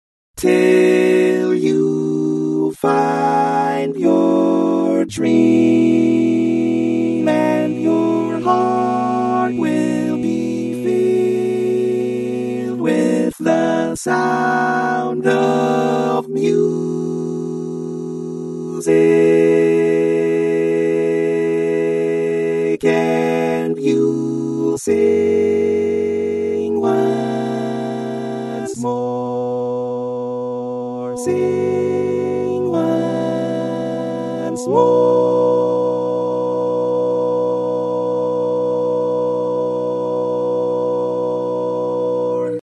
Key written in: B Major
Type: Barbershop